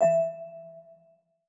Hollow Bell Notification.wav